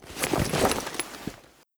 looting_11.ogg